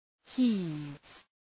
{hi:vz}
heaves.mp3